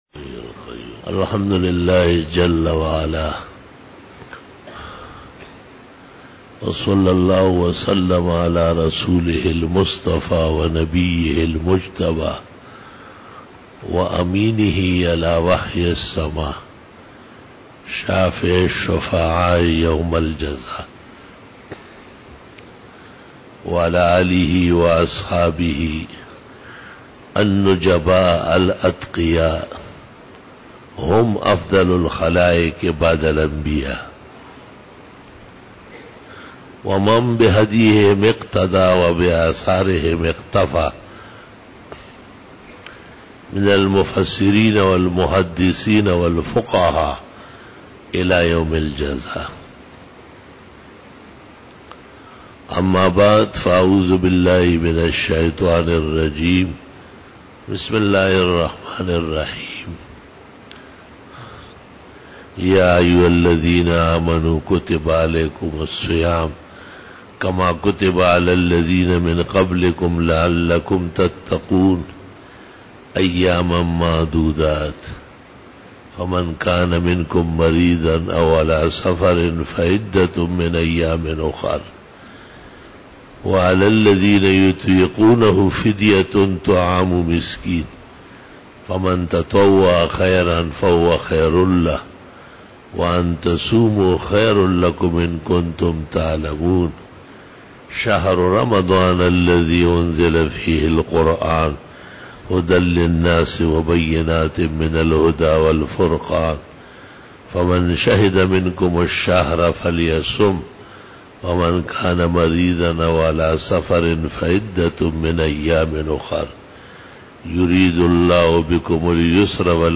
25 BAYAN E JUMA TUL MUBARAK 23 JUNE 2017 (27 Ramadan 1438H)